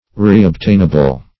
Reobtainable \Re`ob*tain"a*ble\ (-?-b'l), a.
reobtainable.mp3